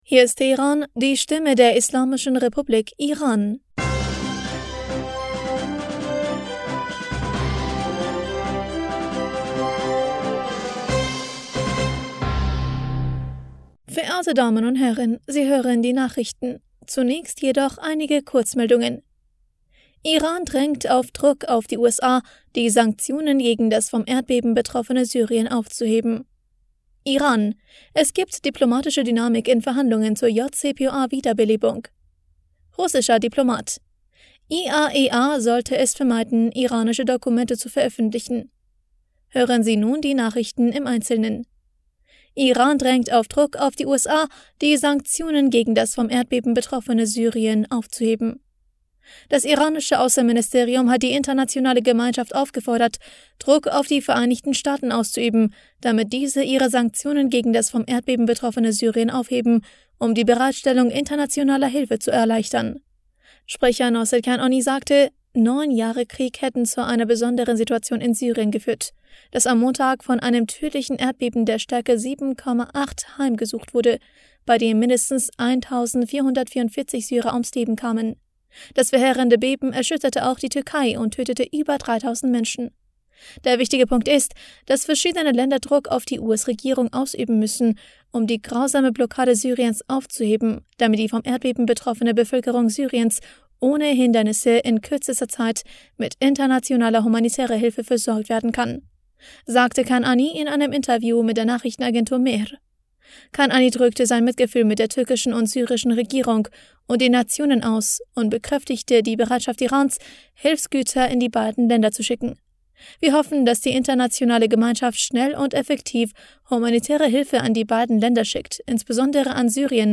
Nachrichten vom 07. Februar 2023